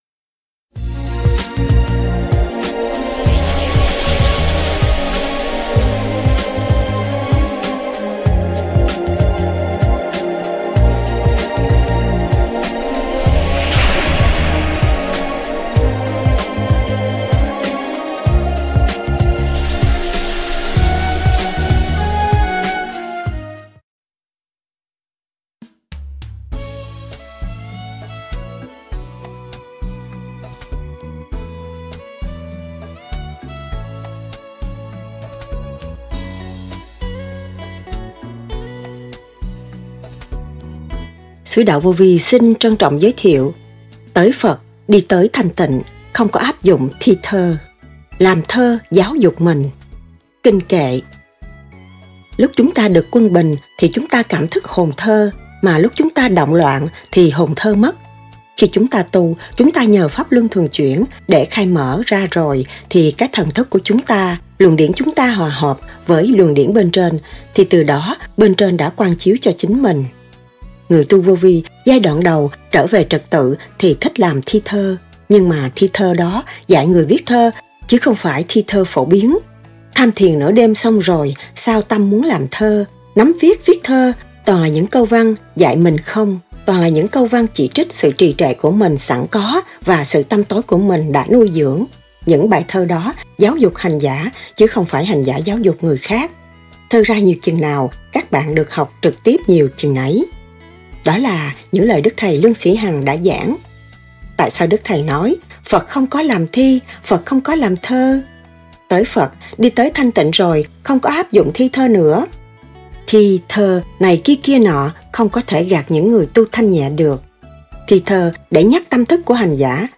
TỚI PHẬT ĐI TỚI THANH TỊNH KHÔNG CÓ ÁP DỤNG THI THƠ-LÀM THƠ GIÁO DỤC MÌNH-KINH KỆ LÀ GÌ ? Lời giảng